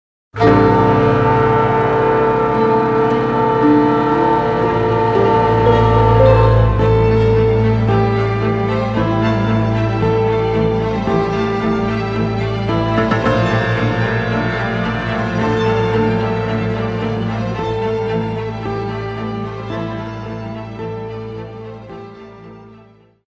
score is a highly dramantic one